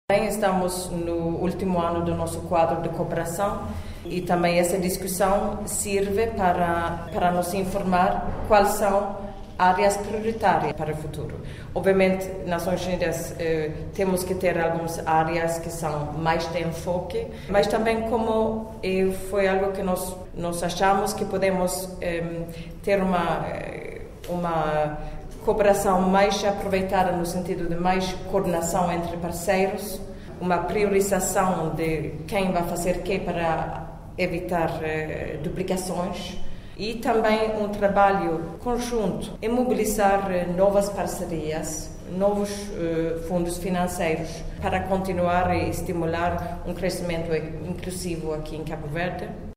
Declarações da representante residente das Nações Unidas em Cabo Verde, Ulrika Richadson à saída de um primeiro encontro com o novo primeiro-ministro de Cabo Verde, Ulisses Correia e Silva, encontro que serviu para perspetivar o novo programa de cooperação entre Cabo Verde e a ONU.